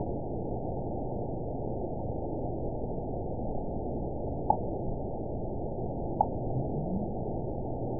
event 912210 date 03/21/22 time 04:03:09 GMT (3 years, 1 month ago) score 9.49 location TSS-AB03 detected by nrw target species NRW annotations +NRW Spectrogram: Frequency (kHz) vs. Time (s) audio not available .wav